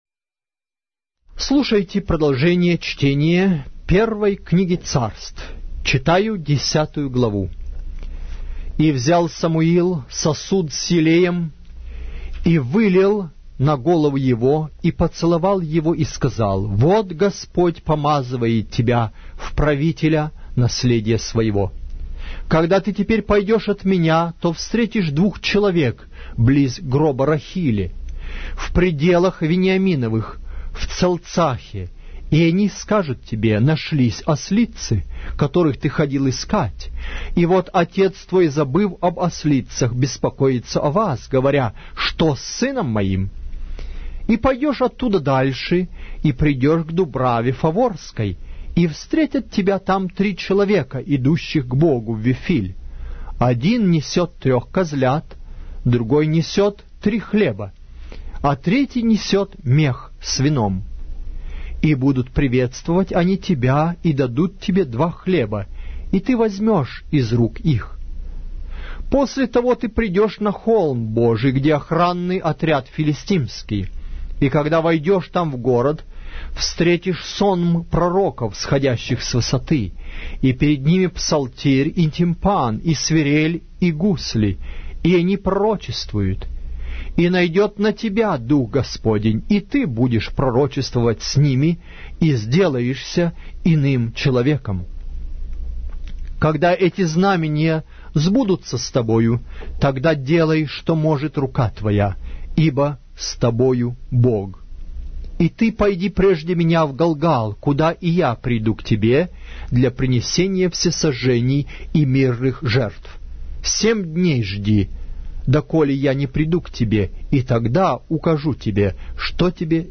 Глава русской Библии с аудио повествования - 1 Samuel, chapter 10 of the Holy Bible in Russian language